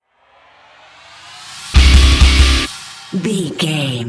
Aeolian/Minor
drums
electric guitar
bass guitar
hard rock
aggressive
energetic
intense
nu metal
alternative metal